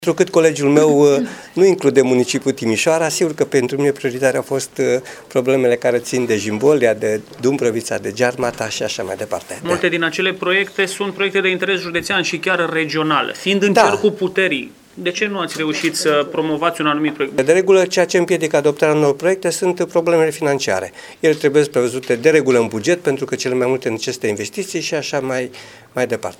Efectele au fost zero, iar deputatul PSD Petru Andea susține că probleme financiare împiedică adoptarea unor proiecte.